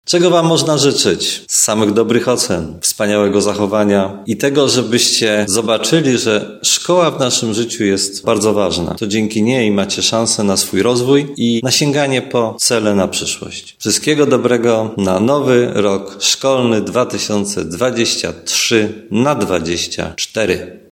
Życzenia na nowy rok szkolny wszystkim uczniom przystępującym do nauki składa prezydent Tarnobrzega Dariusz Bożek, długoletni nauczyciel i dyrektor Liceum Ogólnokształcącego imienia Mikołaja Kopernika.